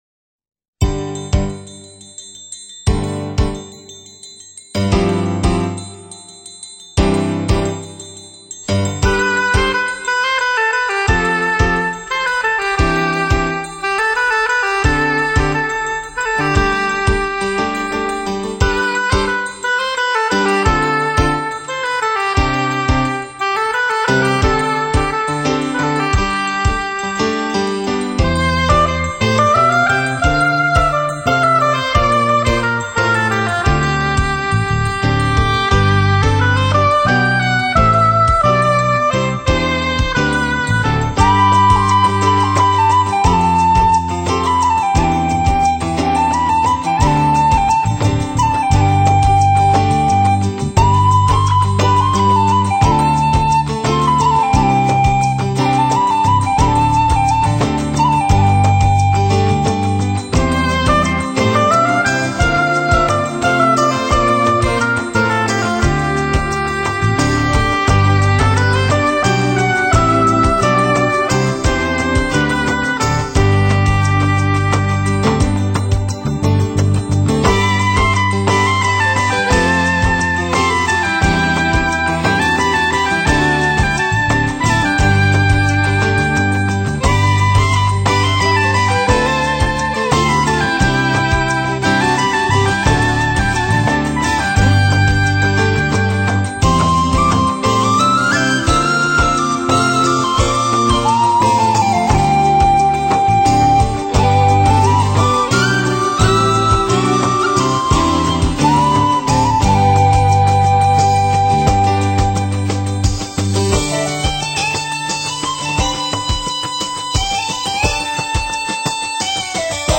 类别：舞剧音乐